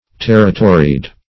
Territoried \Ter"ri*to*ried\